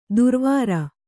♪ durvāra